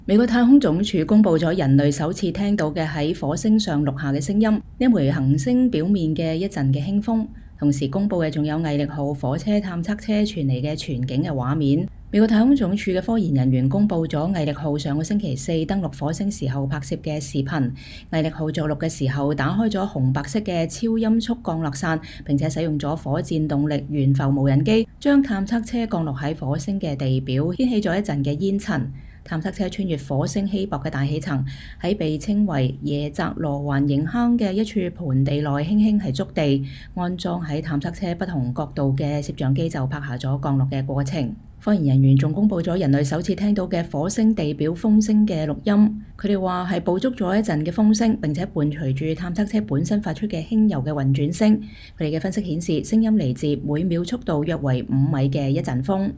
美國太空總署“毅力號”火星車傳回人類首次聽到的火星錄音
美國太空總署(NASA)公佈了人類首次聽到的在火星上錄下的聲音 - 這顆行星表面的一陣輕風。